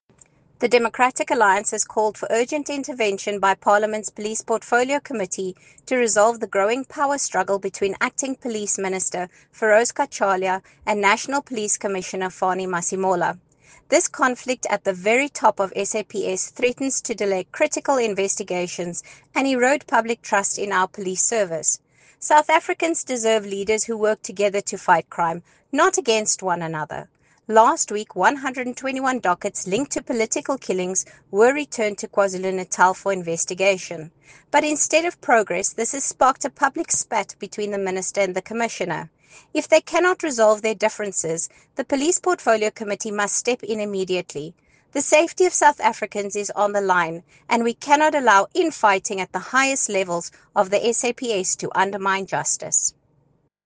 Issued by Lisa Schickerling MP – DA Spokesperson on Police